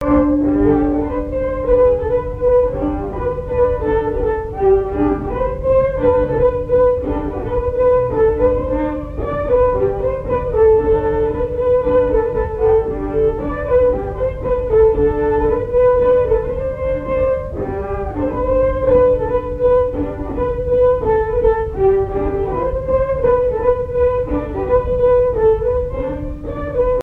Mémoires et Patrimoines vivants - RaddO est une base de données d'archives iconographiques et sonores.
danse : mazurka-polka
Airs à danser aux violons
Pièce musicale inédite